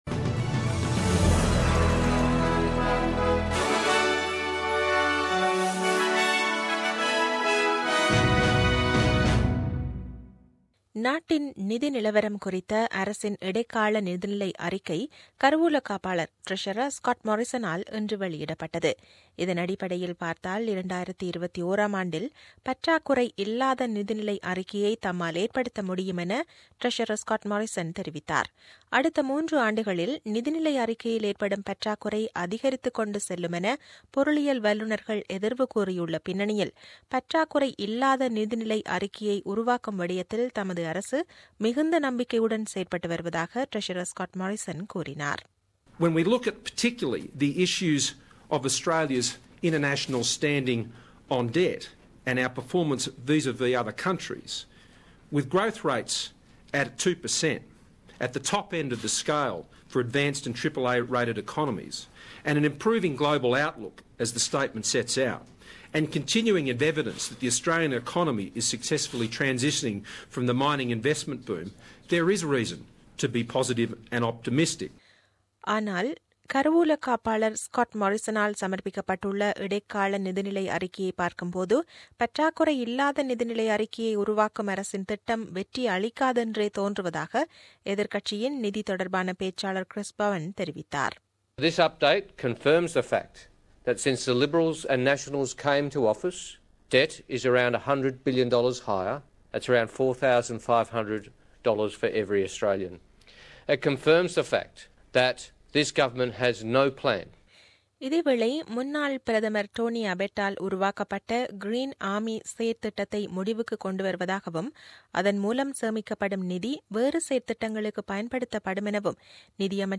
The news bulletin aired on 19 Dec 2016 at 8pm.